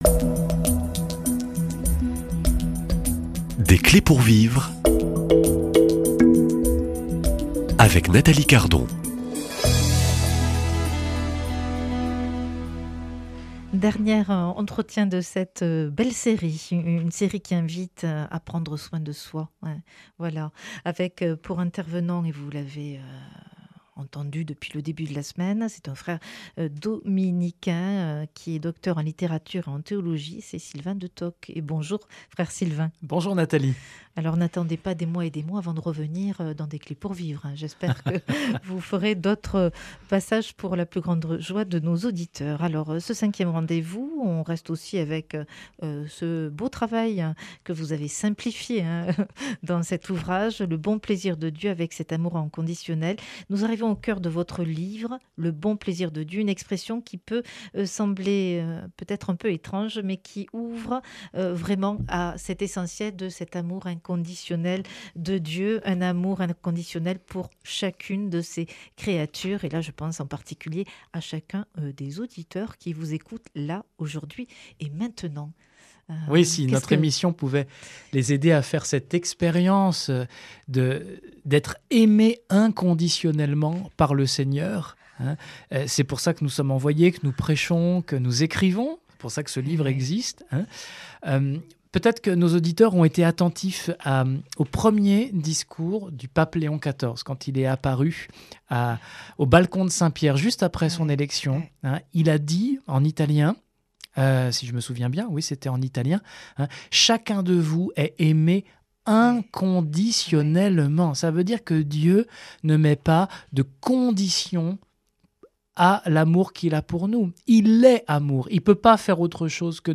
Des conseils concrets, des gestes simples et des témoignages qui montrent qu’une foi joyeuse est à la portée de tous. Invité